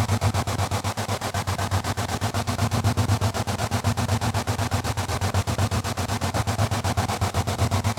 Index of /musicradar/stereo-toolkit-samples/Tempo Loops/120bpm
STK_MovingNoiseE-120_03.wav